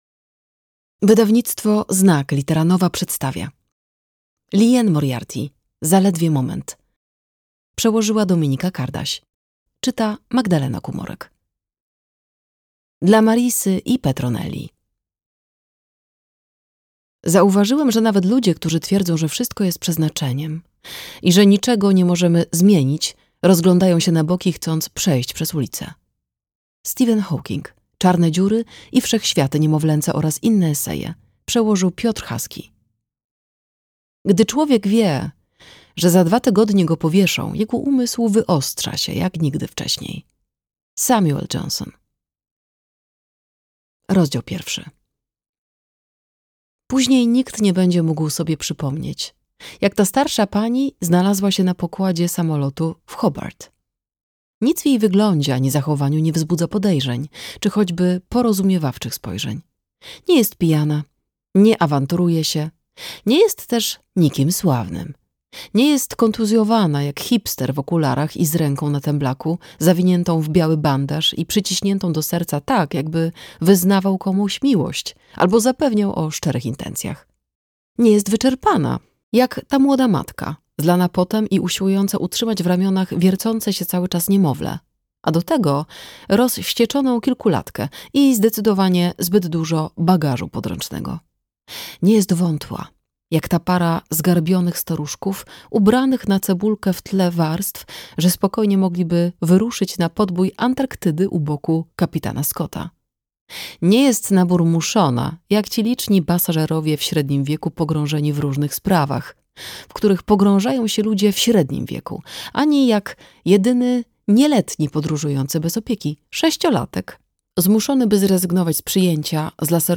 Zaledwie moment - Liane Moriarty - audiobook